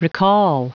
Prononciation du mot recall en anglais (fichier audio)